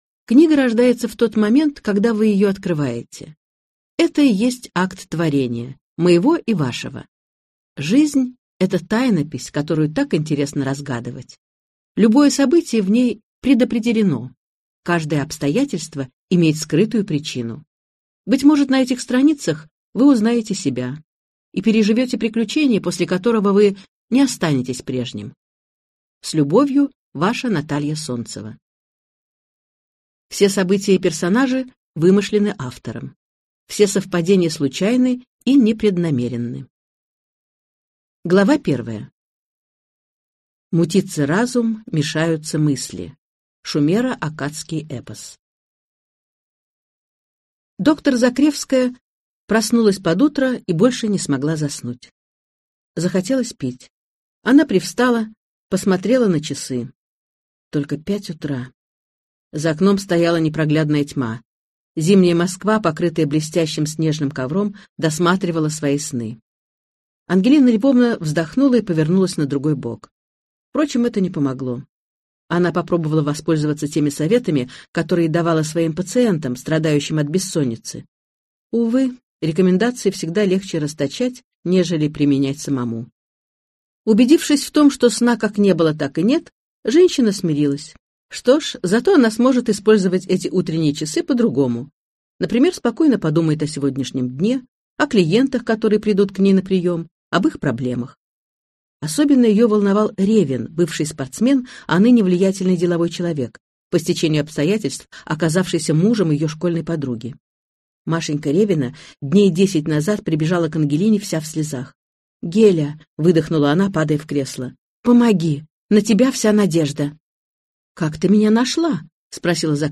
Аудиокнига В храме Солнца деревья золотые | Библиотека аудиокниг